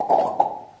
大规模录音根特" 002年手指弹出的混乱T2
描述：声音是在比利时根特的大规模人民录音处录制的。
一切都是由4个麦克风记录，并直接混合成立体声进行录音。每个人都用他们的手指在嘴里发出啪啪的声音。混乱的，没有时间或平移。